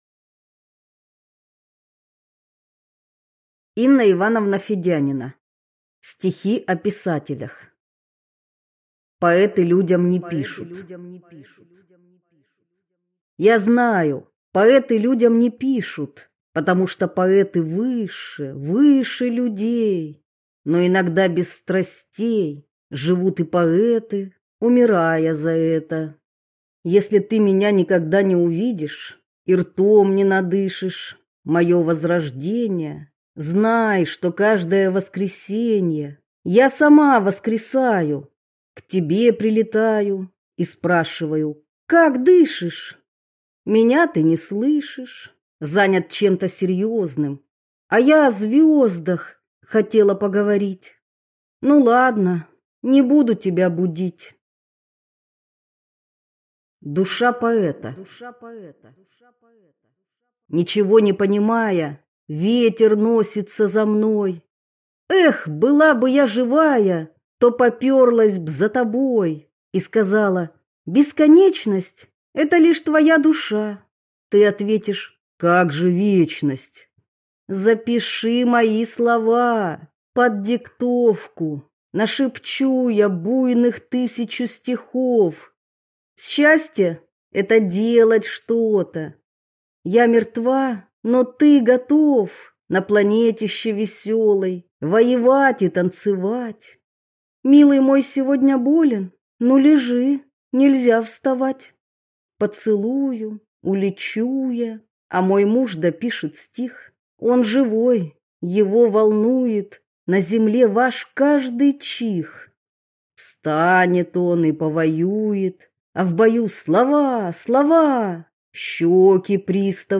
Аудиокнига Стихи о Писателях и Творцах | Библиотека аудиокниг